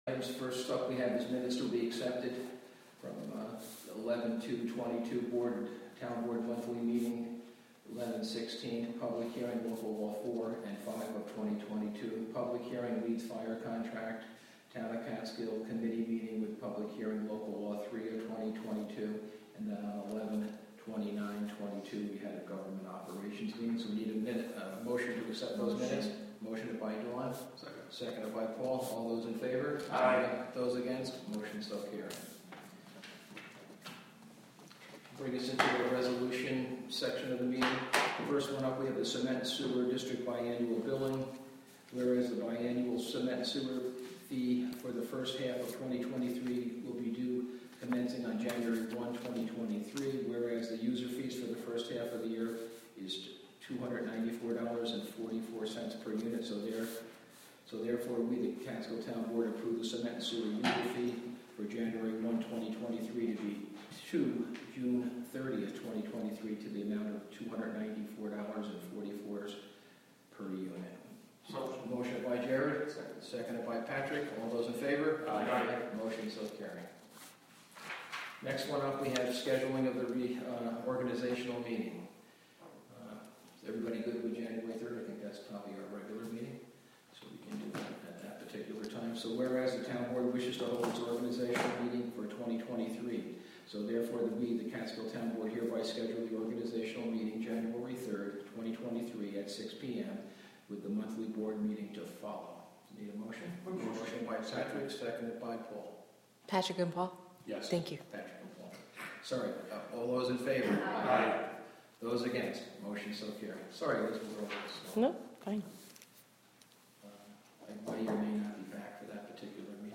Live from the Town of Catskill: December 6, 2022 Town Board Meeting (Audio)